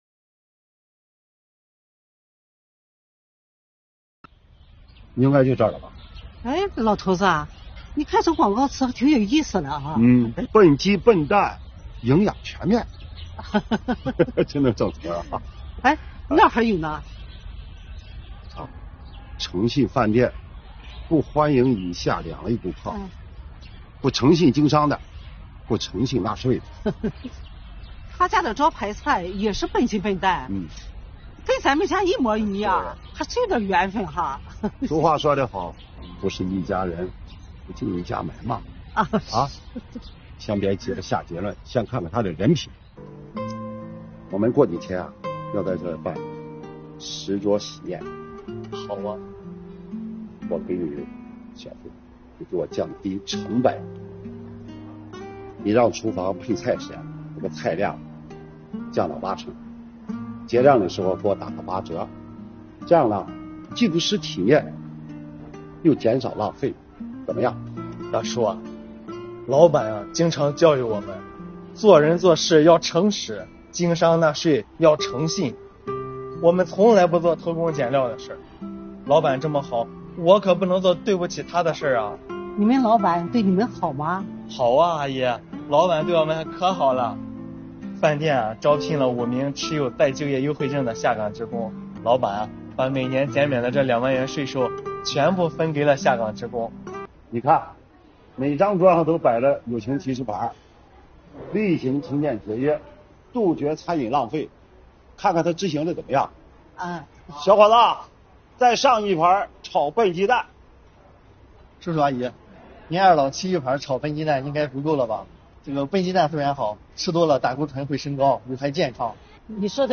作品通过情景剧来引导纳税人树立诚信纳税意识，宣扬诚信经营、依法纳税精神。